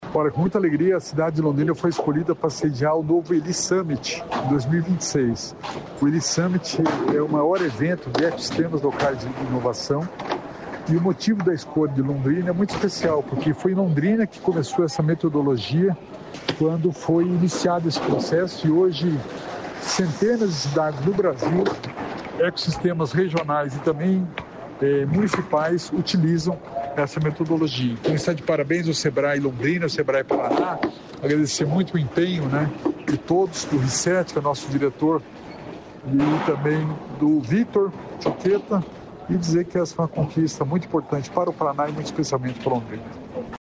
Sonora do secretário estadual da Inovação e Inteligência Artificial, Alex Canziani, sobre fórum de inovação